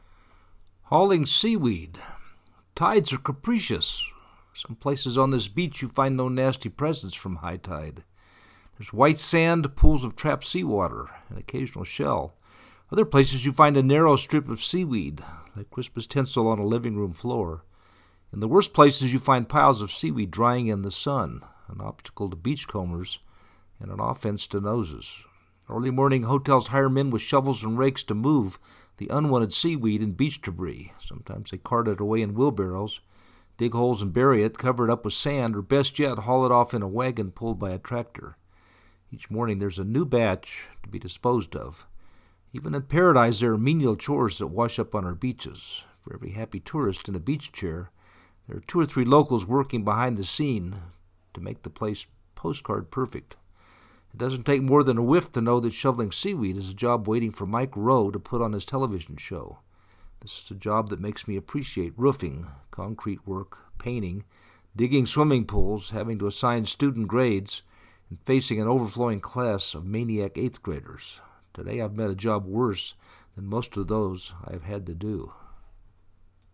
hauling-seaweed-3.mp3